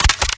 Rocket Launcher
GUNMech_Rocket Launcher Reload_04_SFRMS_SCIWPNS.wav